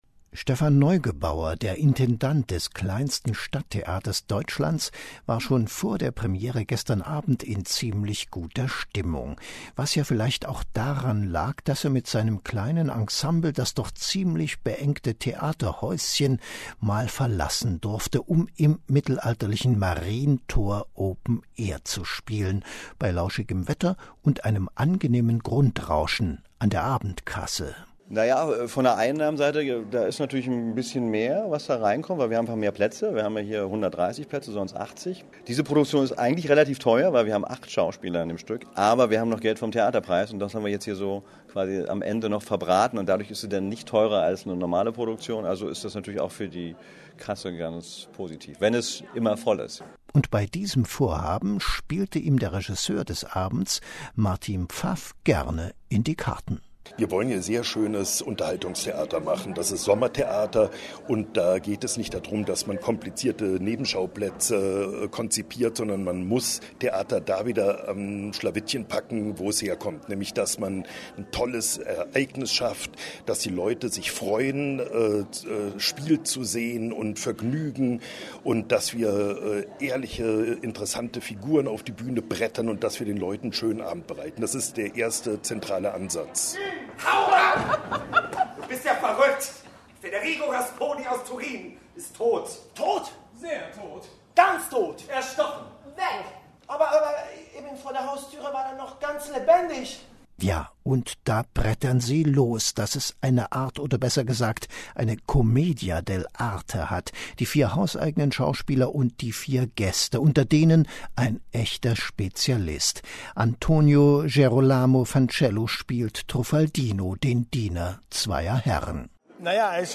Radiobeitrag zum Sommertheater
Zum Nachhören das Radio-Feature vom Mitteldeutschen Rundfunk zur Aufführung "Diener zweier Herren", welches am 8. Juni über den Äther lief.